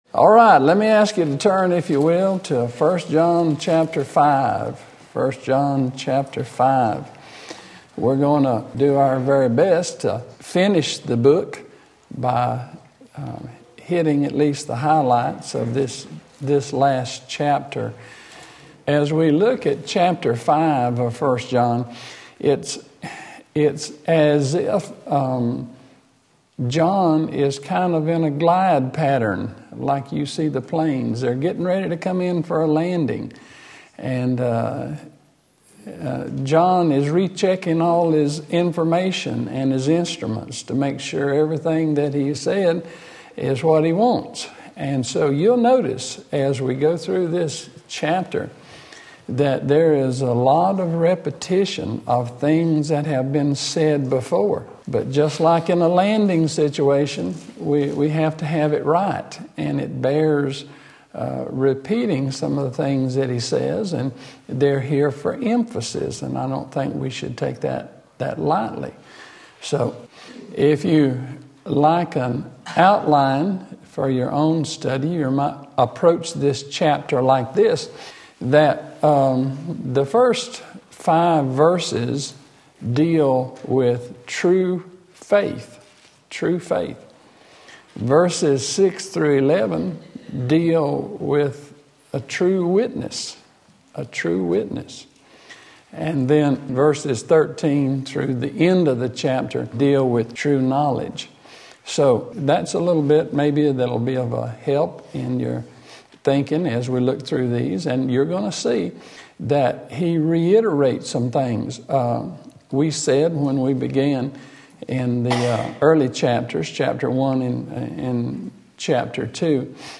and True Knowledge 1 John 5 Sunday School